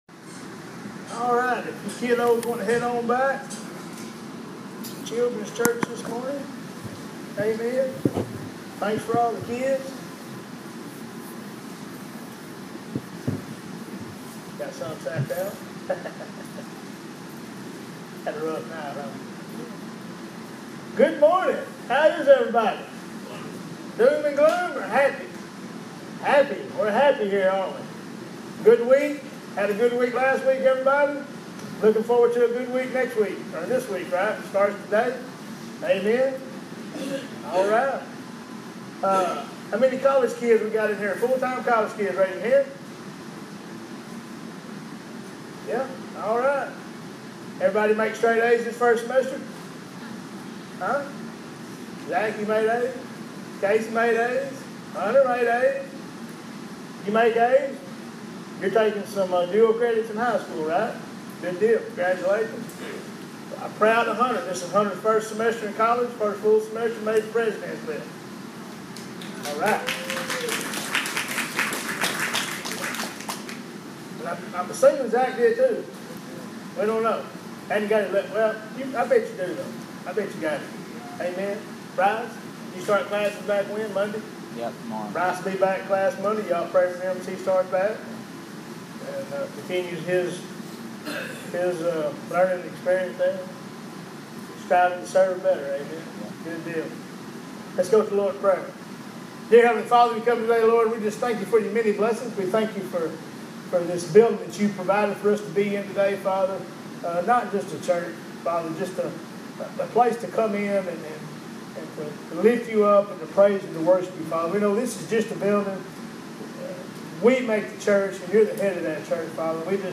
Sermons - Crimson River Church & Ministries